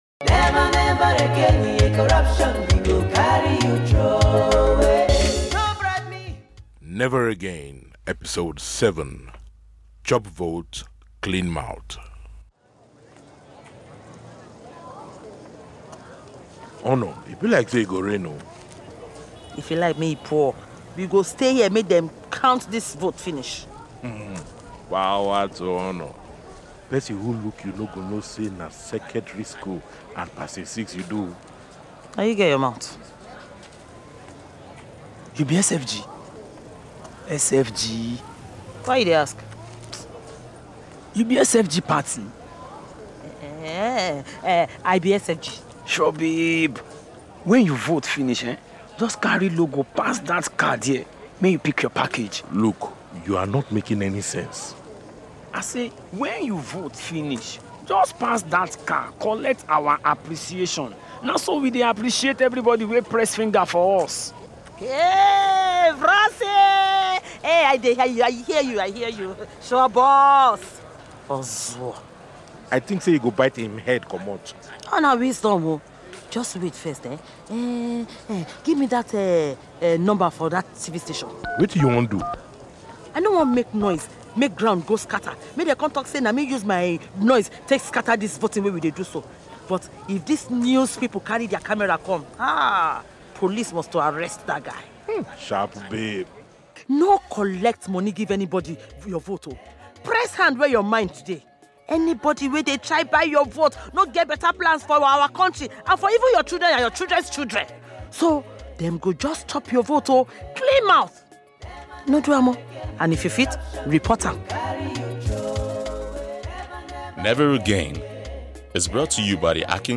“Never Again” Radio Drama